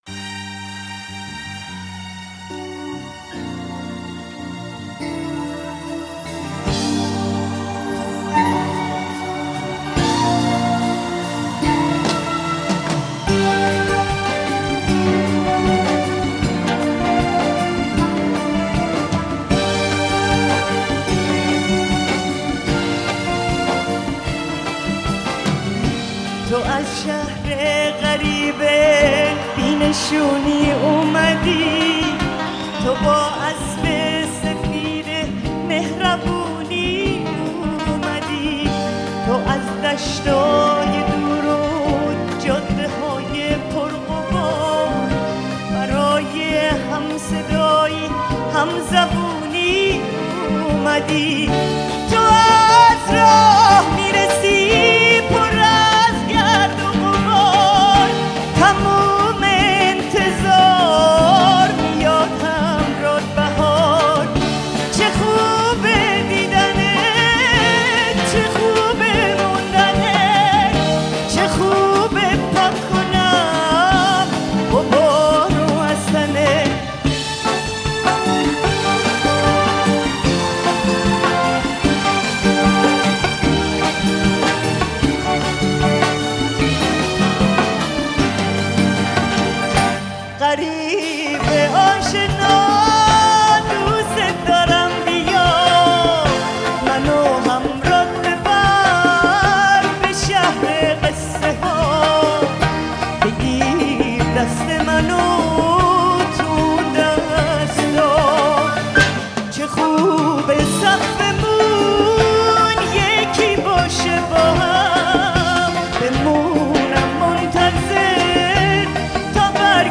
دانلود Live (زنده)